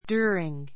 djú(ə)riŋ